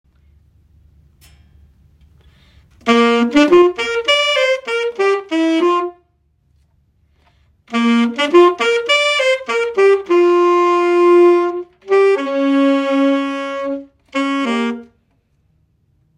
Saksofoni
Saksofoni.m4a